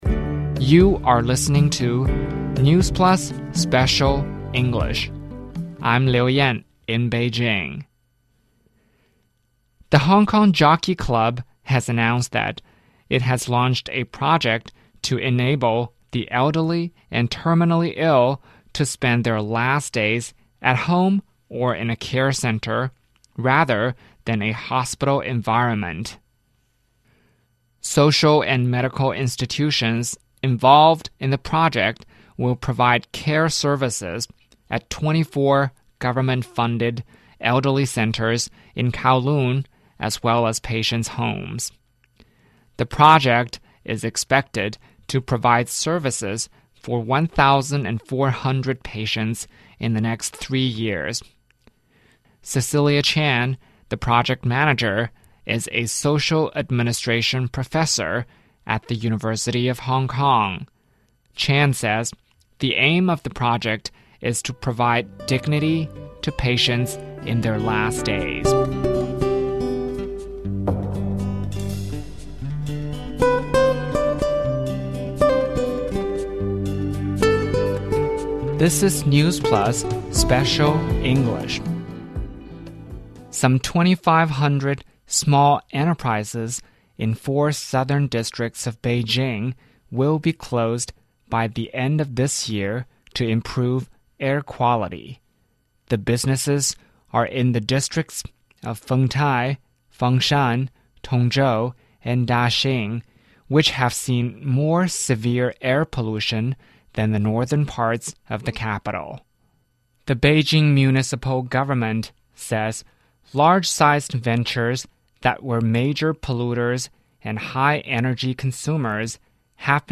NEWS Plus慢速英语:香港赛马会助晚期患者走完人生 北京今年将清退2500家污染企业